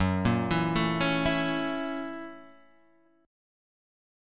F# 7th Sharp 9th